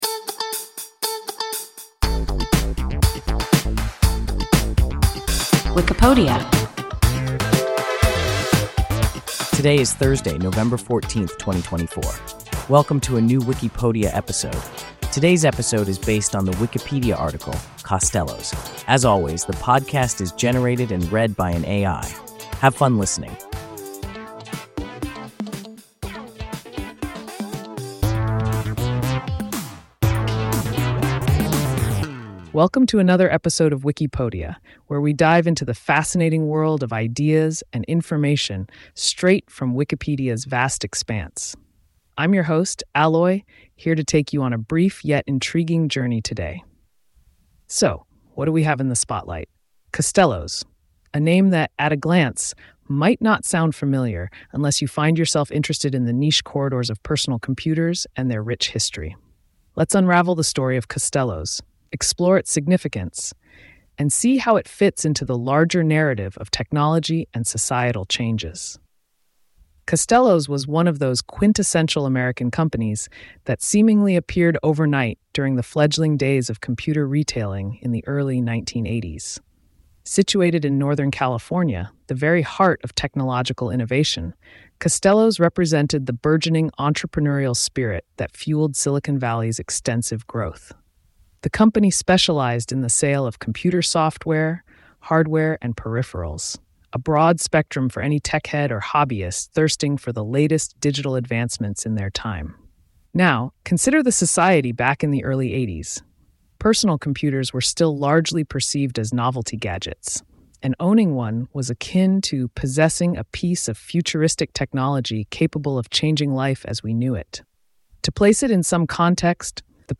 Costello’s – WIKIPODIA – ein KI Podcast